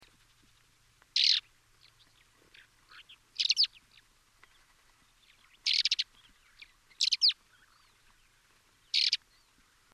Pikkukiuru / Lesser Short-toed Lark / Calandrella rufescens
1. Southern Kazakstan
Calls, recorded 6 July 2005 near Kolshengel, Taukum Desert, Almaty oblasti, Kazakstan. Two different types of call alternating